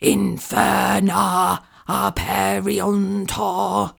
mandrake fvttdata/Data/modules/psfx/library/incantations/older-female/fire-spells/inferna-aperiuntur
inferna-aperiuntur-slow.ogg